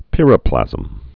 (pĭrə-plăzəm)